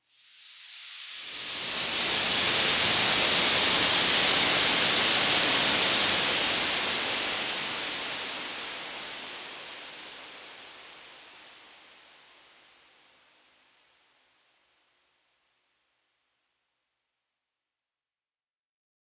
Index of /musicradar/shimmer-and-sparkle-samples/Filtered Noise Hits
SaS_NoiseFilterD-03.wav